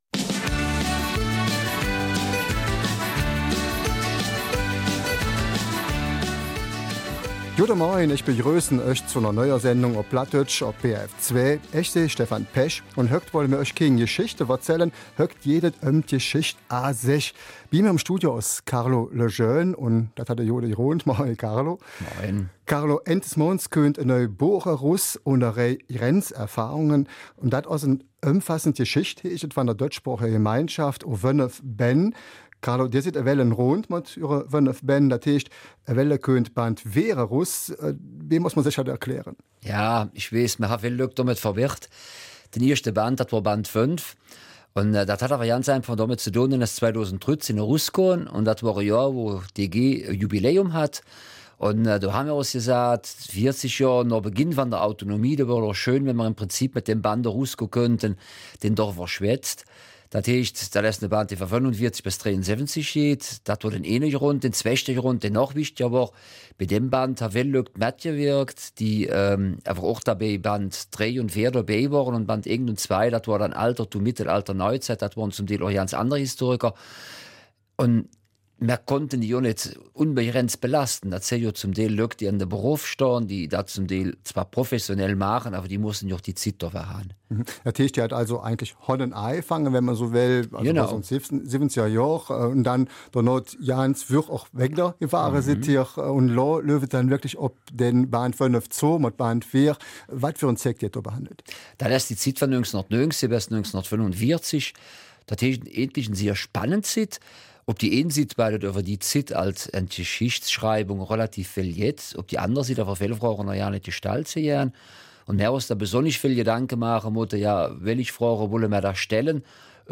Eifeler Mundart: Band 4 der ''Grenzerfahrungen''